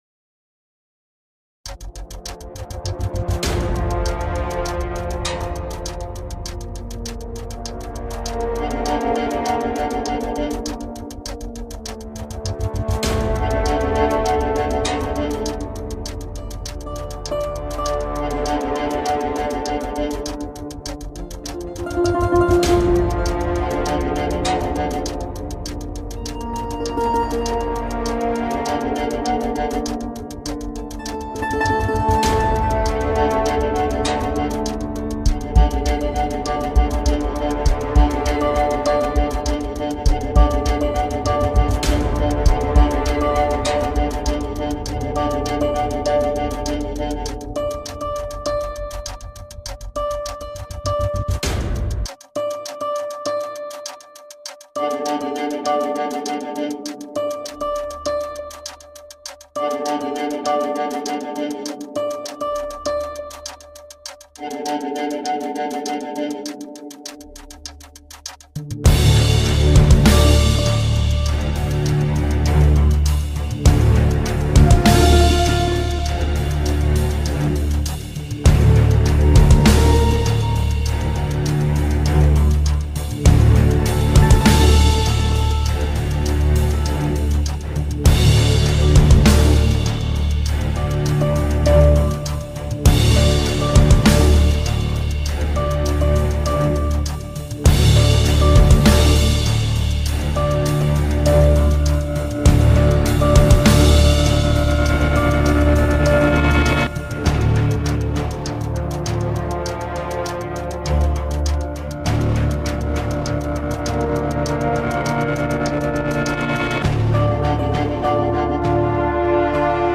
tema dizi müziği, heyecan gerilim aksiyon fon müzik.